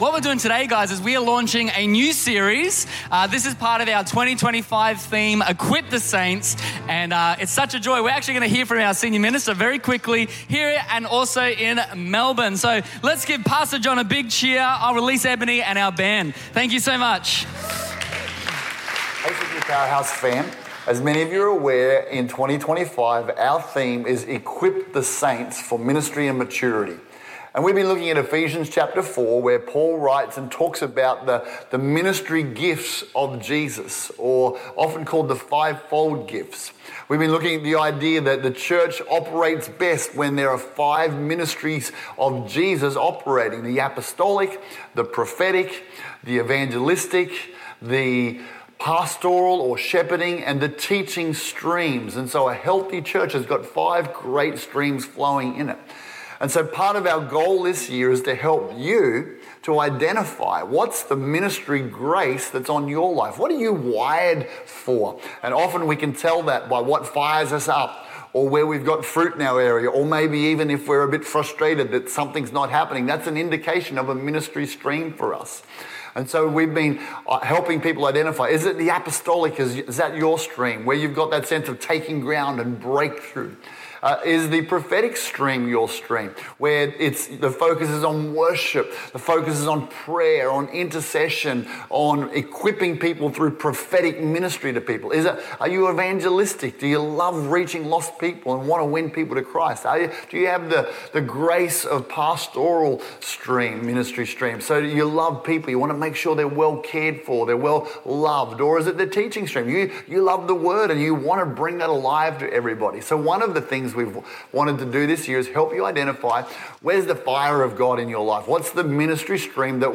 This message is from one of our Sunday church services.